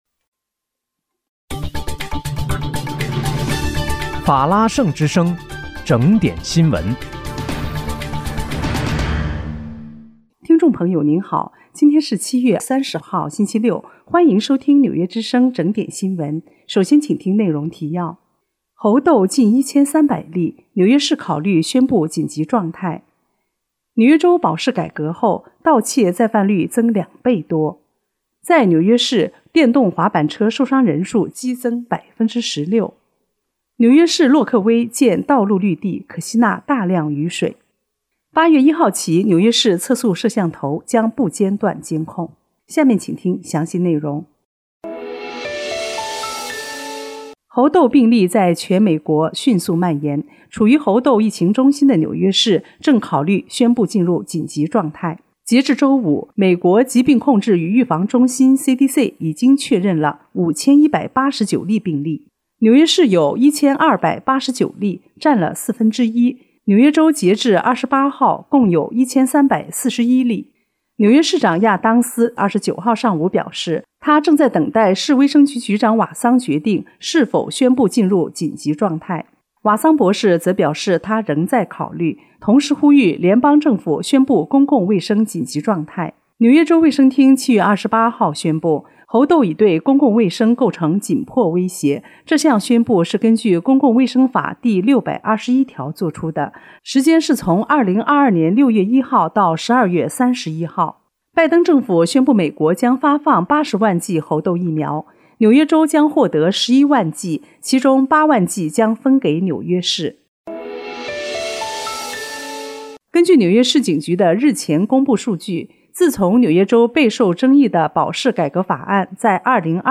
7月30日（星期六）纽约整点新闻